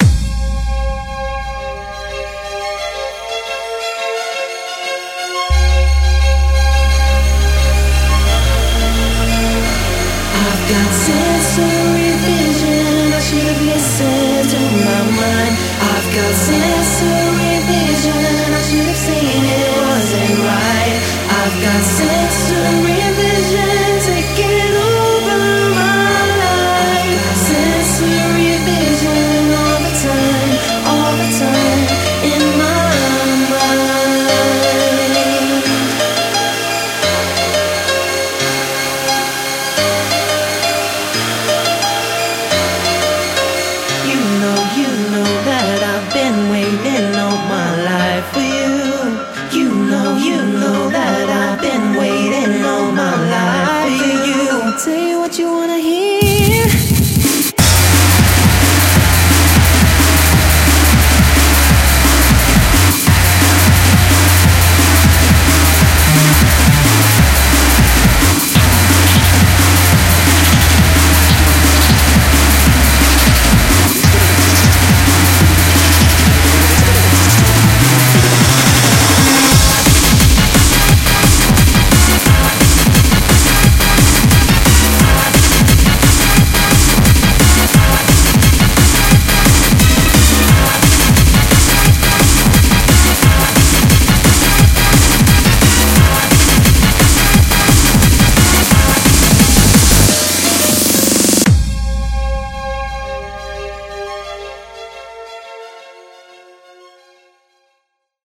BPM175
Audio QualityPerfect (High Quality)